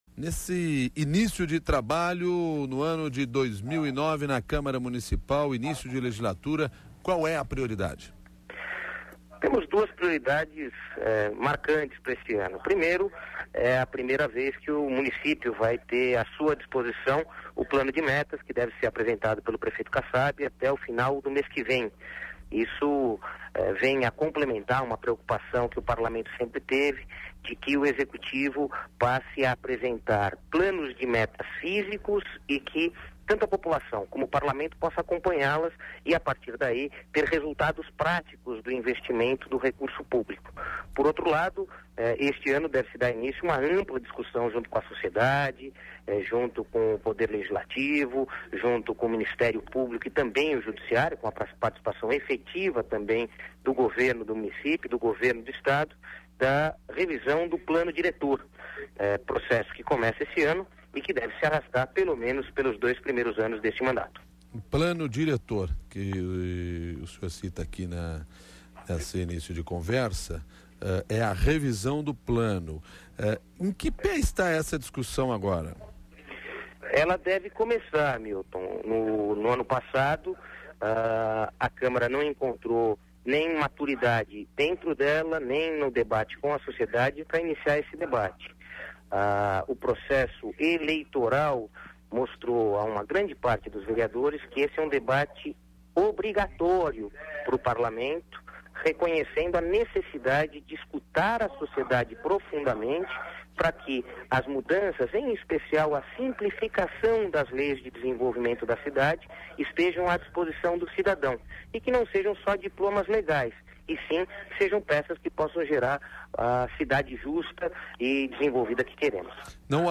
Ouça a entrevista com o líder do Governo,